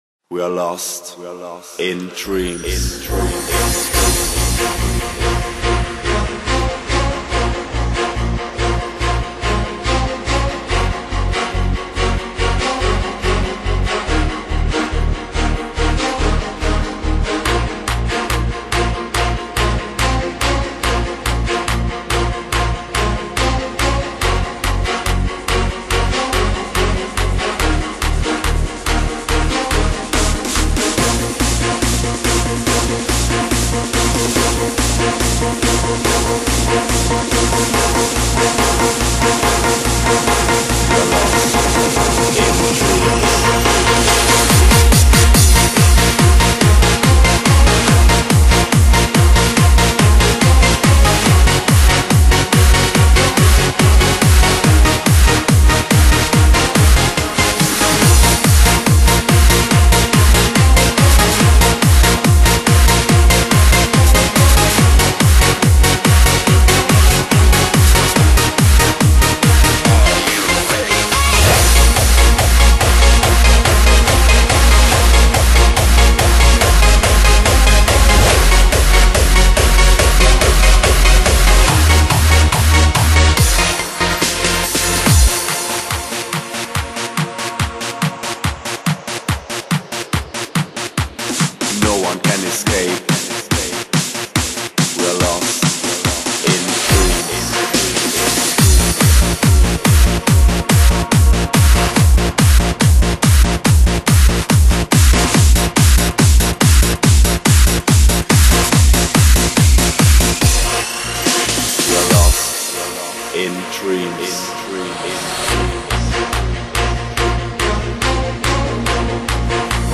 極品電音
TRANCE音乐，几乎成为这个世界上最具影响力的电子舞曲形式。